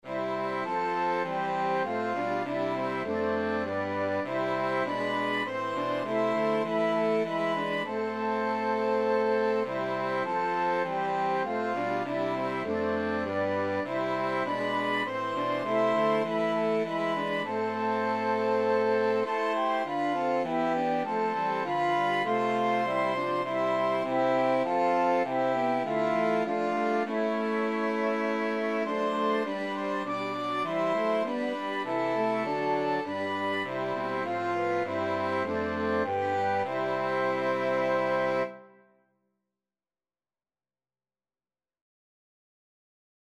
FluteViolin
French Horn
Baritone Saxophone
4/4 (View more 4/4 Music)